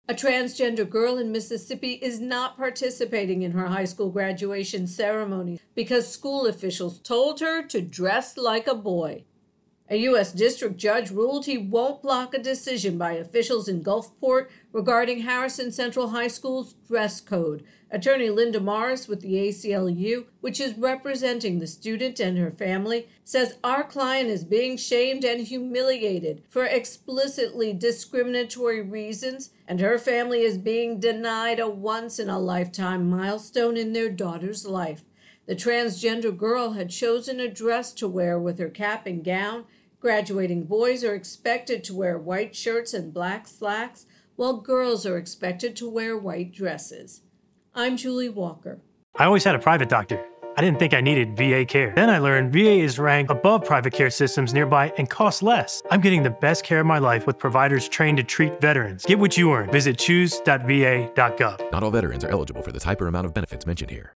reports on Transgender Student Graduation